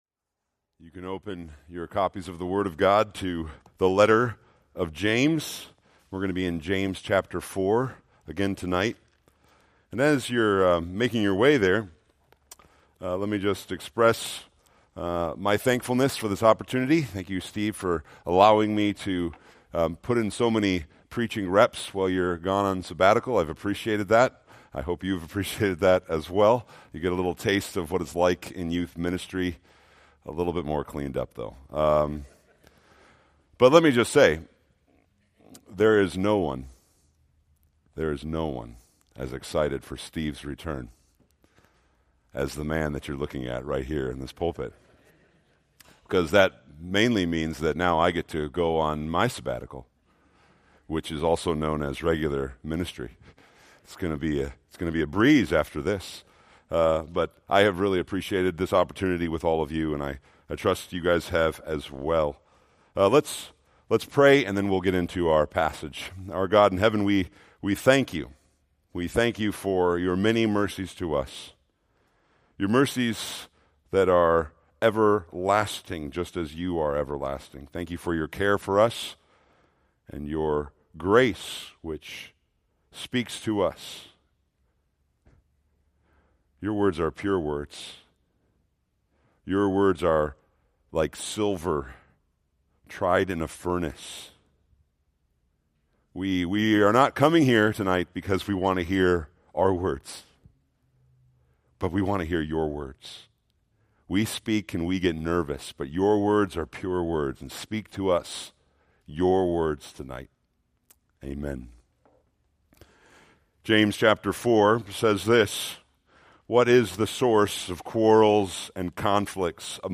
Preached August 18, 2024 from James 4:6-10